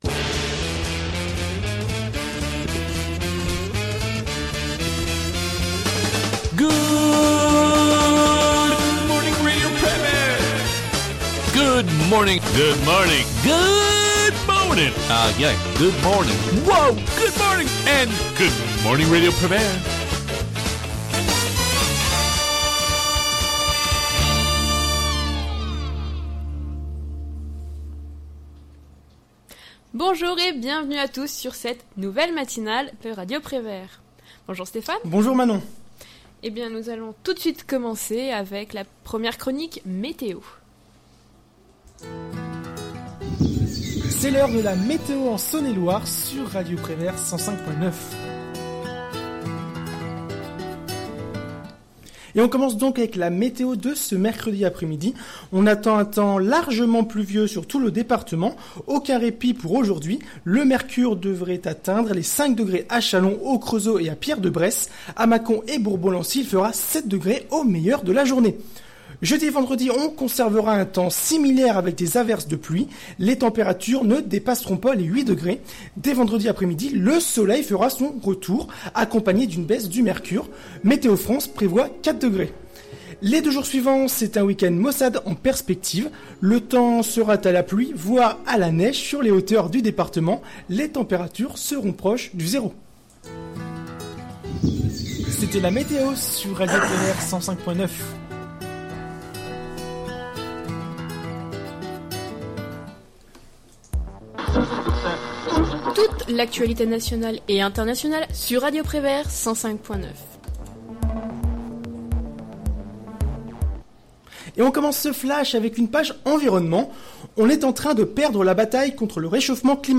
Good Morning Prévert, la matinale Podcasts